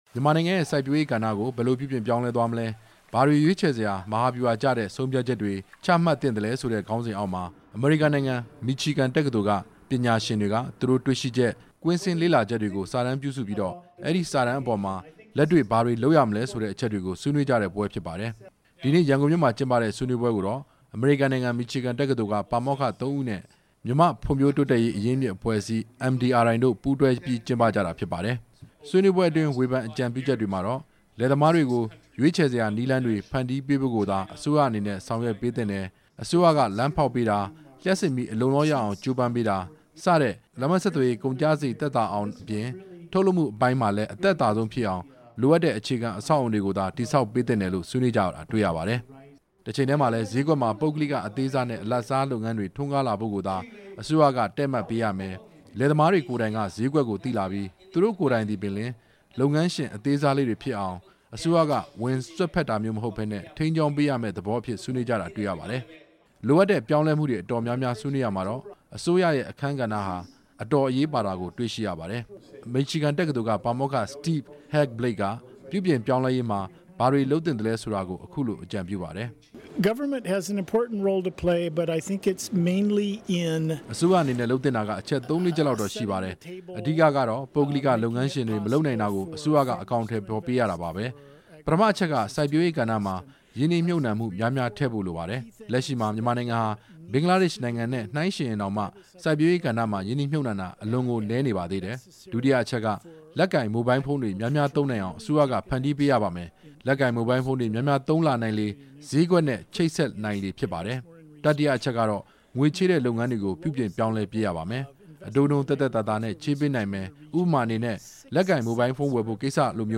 ဆွေးနွေးပွဲ တင်ပြချက်